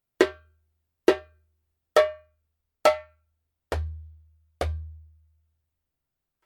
Wood : レッドウッド Red Wood (Diala, Acajou, Bois Rouge)
透明感があってはぎれよい鳴り、軽く鳴らせてとてもたたきやすい太鼓です。